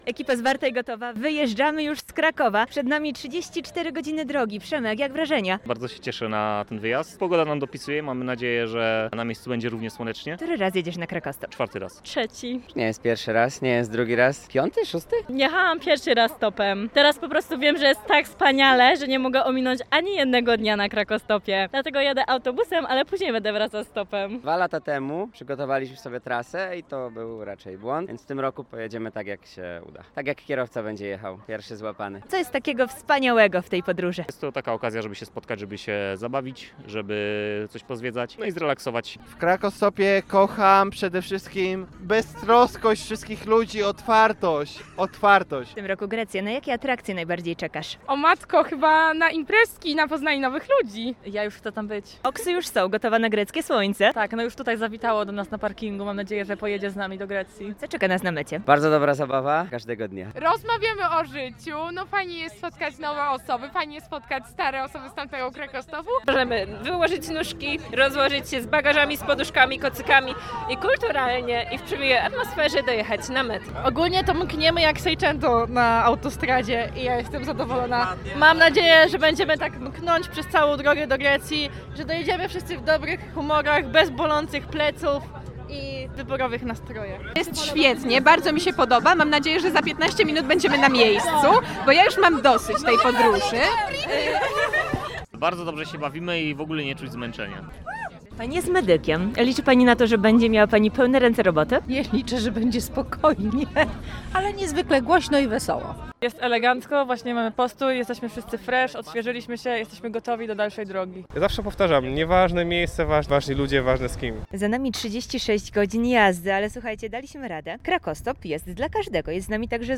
Relacja-z-Krakostopu.mp3